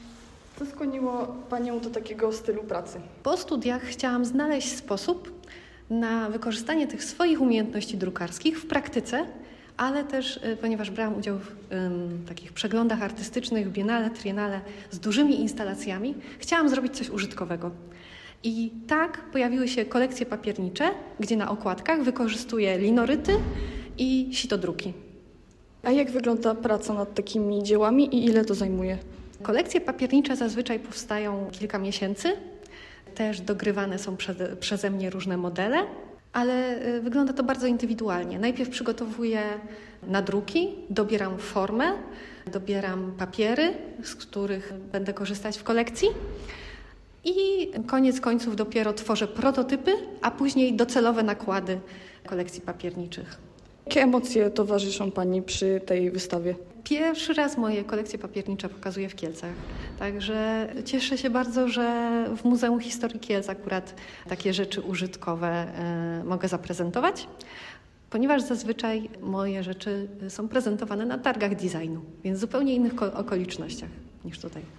Wernisaż w Muzeum Historii wywiad